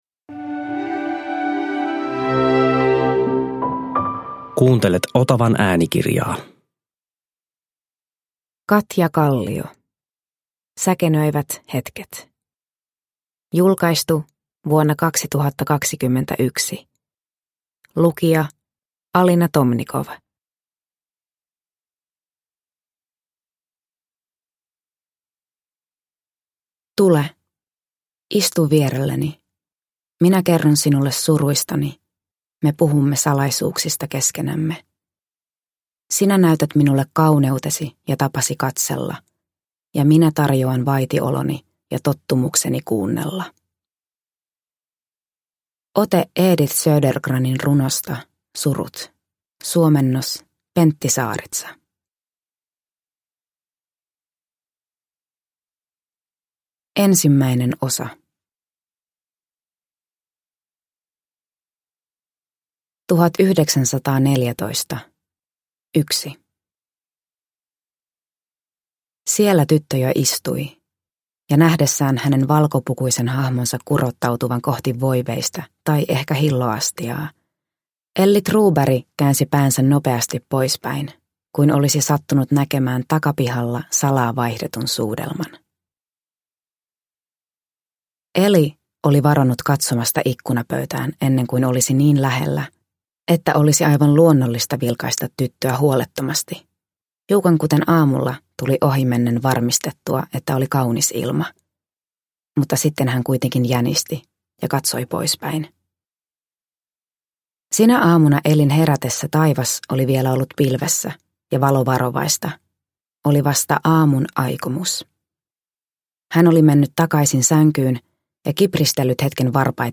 Säkenöivät hetket – Ljudbok – Laddas ner